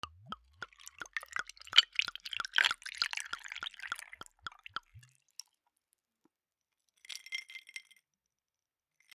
ブランデーグラス 氷(ロックアイス) 水を注ぐ
酒 バー